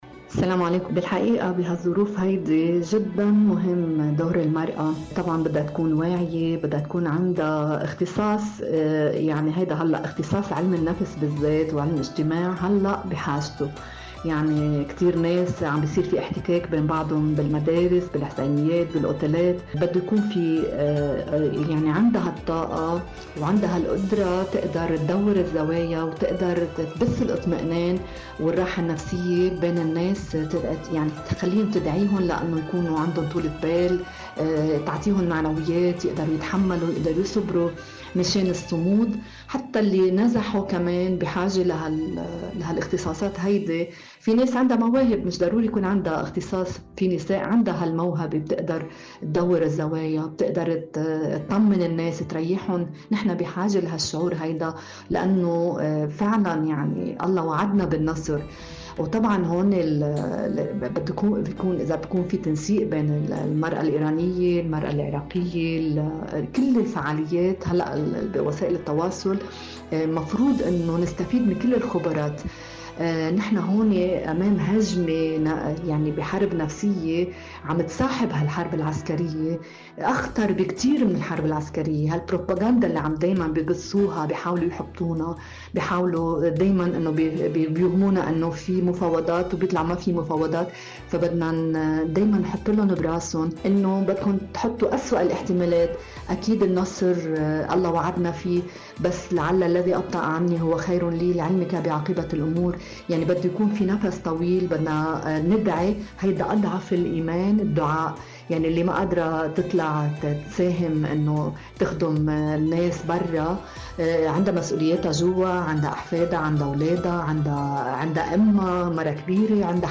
إذاعة طهران- المنتدى الإذاعي: